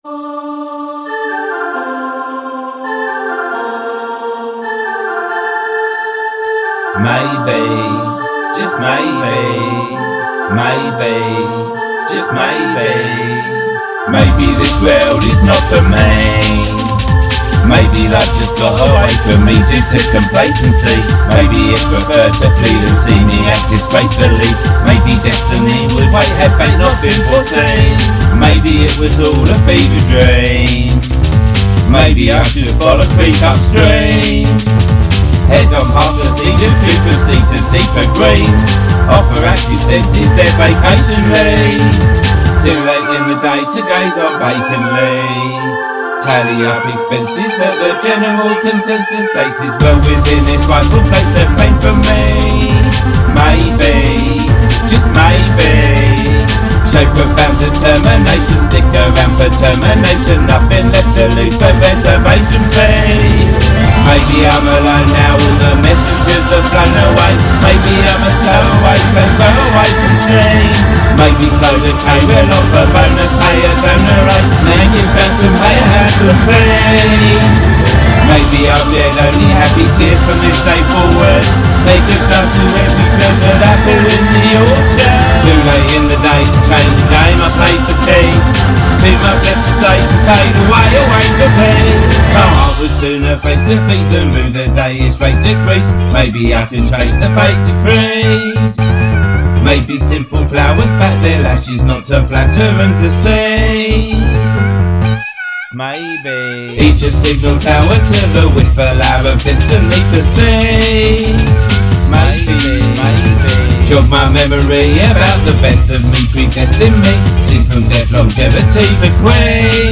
downbeat in tone